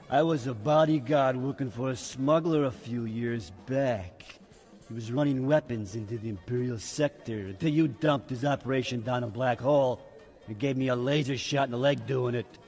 ―The Gran recounts his first encounter with Kyle Katarn — (audio)
He had a voice that sounded like a gravel crusher stuck in low gear[4] and could speak both an alien language[2] and Basic.[3]